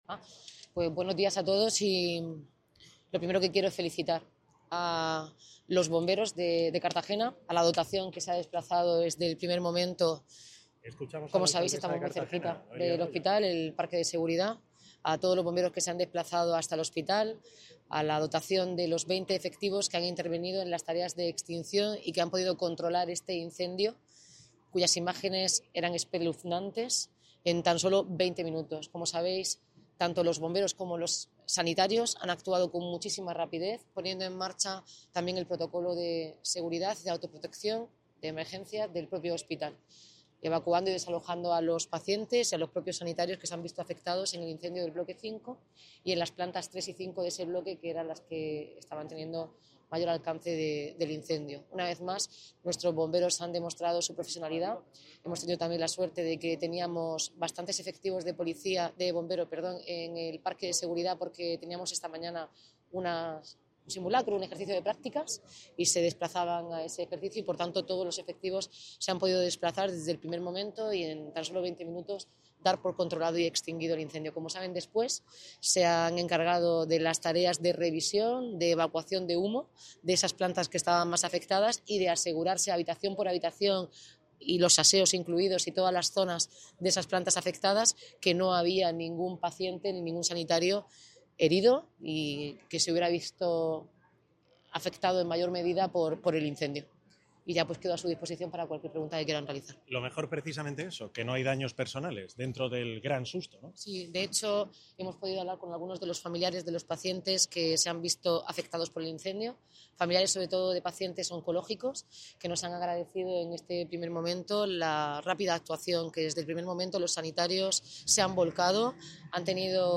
Audio: Declaraciones de la alcaldesa Noelia Arroyo. (MP3 - 1,22 MB)